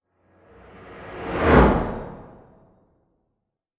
Звуки перехода, смены кадра
UFO вжух